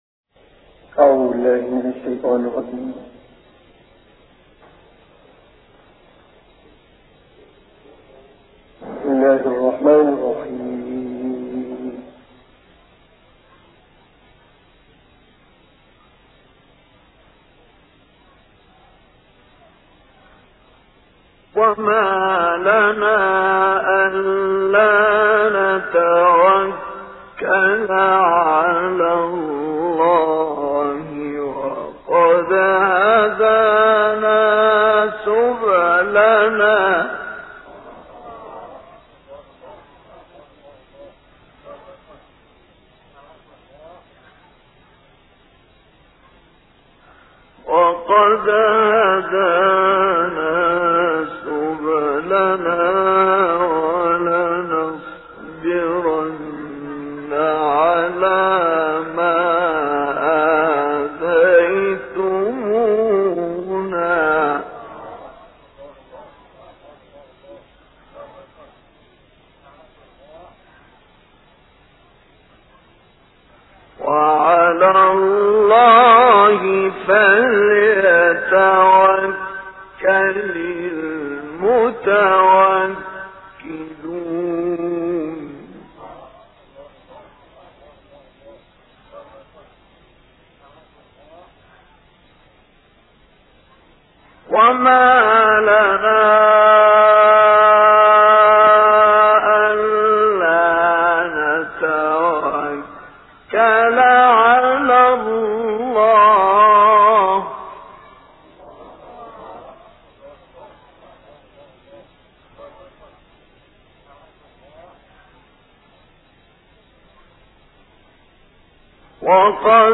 تلاوات قرآنية